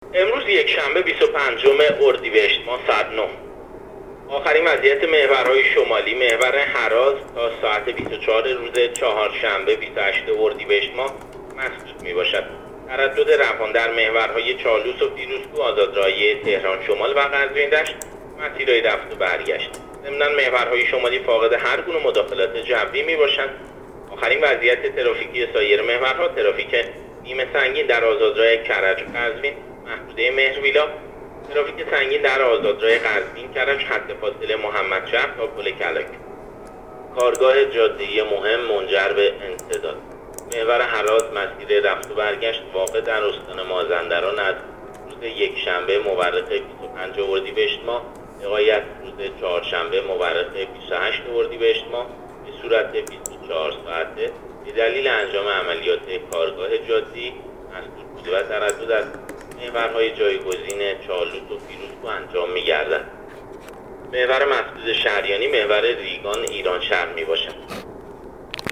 گزارش رادیو اینترنتی از آخرین وضعیت ترافیکی جاده‌ها تا ساعت ۱۵ بیست و پنجم اردیبهشت؛